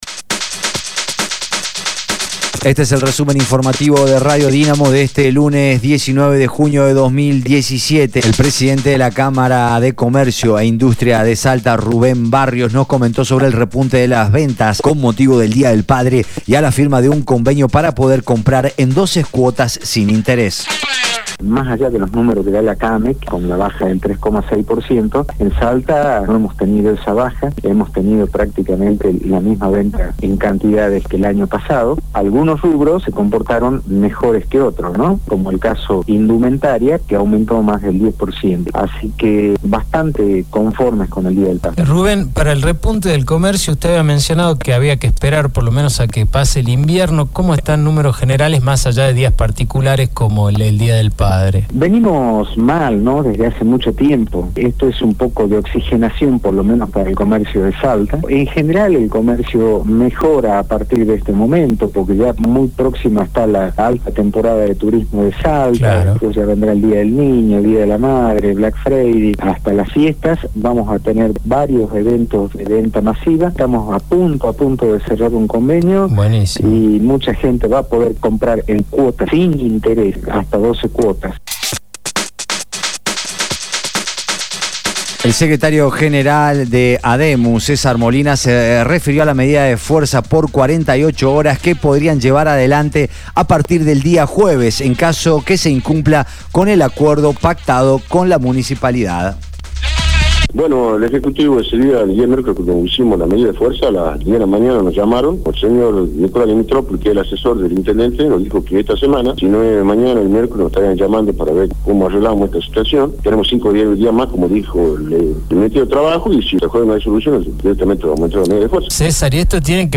Resumen Informativo de Radio Dinamo del día 19/06/2017 2° Edición